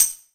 9TAMBOURIN18.wav